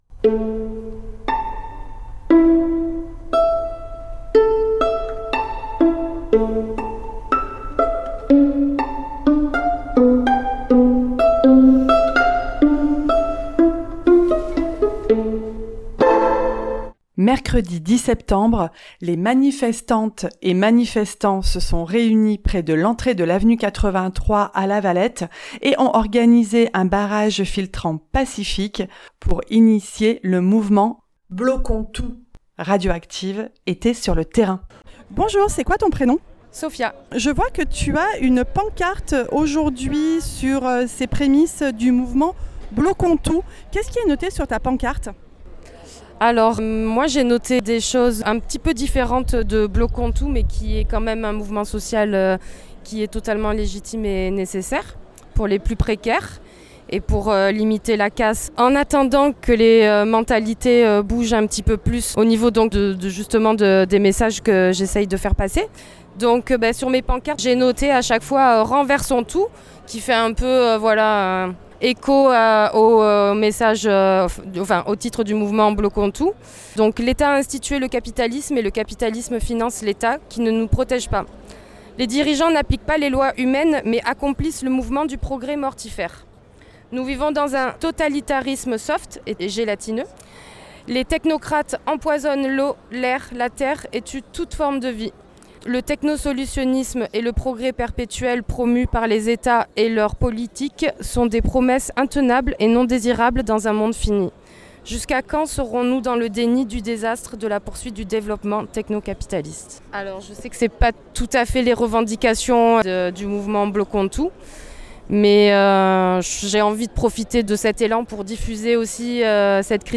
Interview 4 (10 septembre 2025)